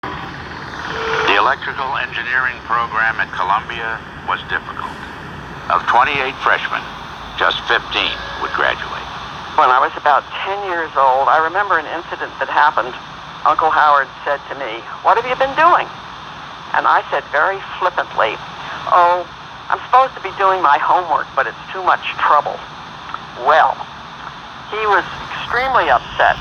On Friday, 19 June, a historic transmission from the Alpine Tower on the NY/NJ line (right off the Palisades) was made.